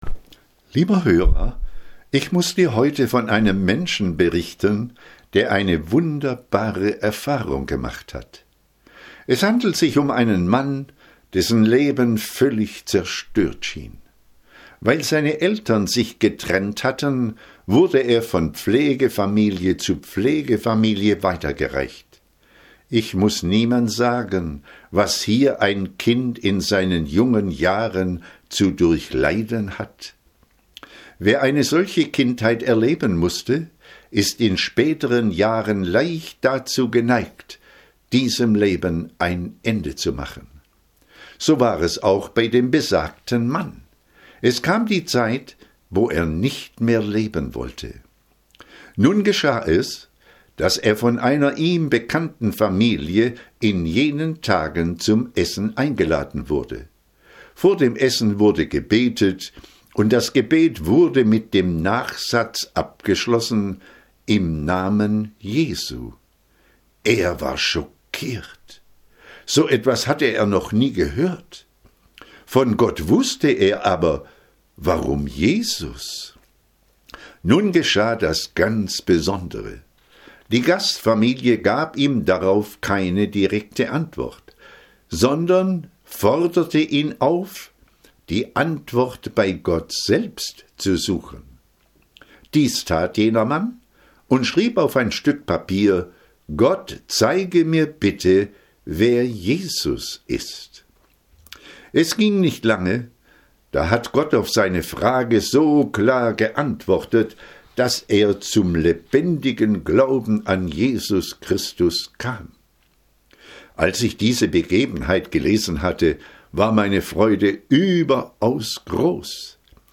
Letzte Predigten